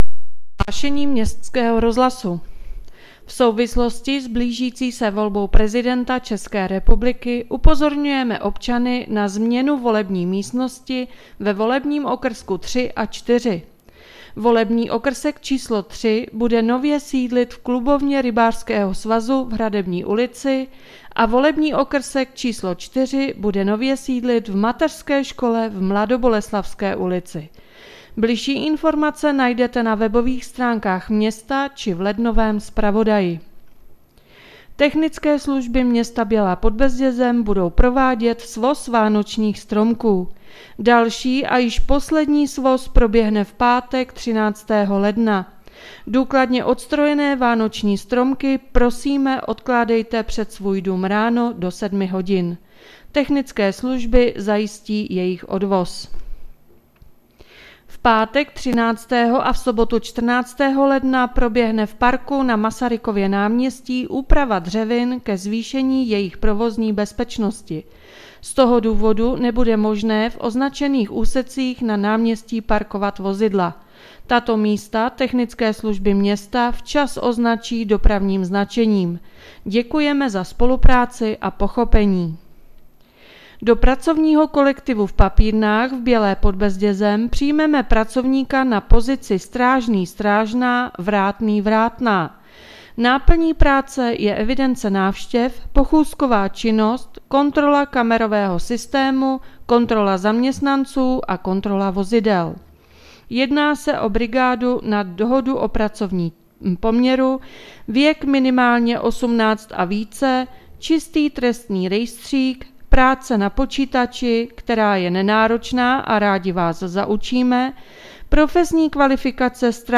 Hlášení městského rozhlasu 11.1.2023